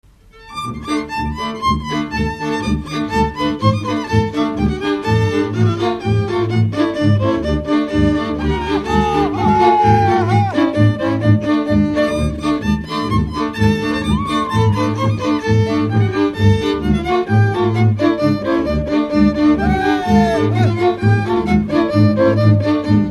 Dallampélda: Hangszeres felvétel
Dunántúl - Somogy vm. - Berzence
Műfaj: Ugrós
Stílus: 6. Duda-kanász mulattató stílus